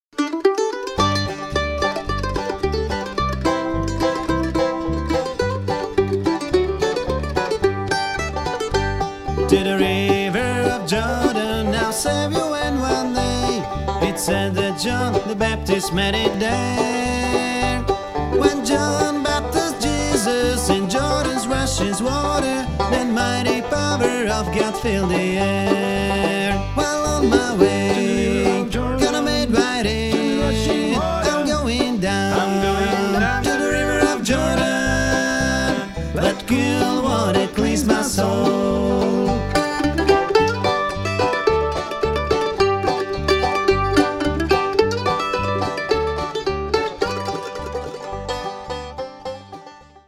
mandolína, lead, tenor vokál
banjo, tenor vokál
gitara, lead, bariton vokál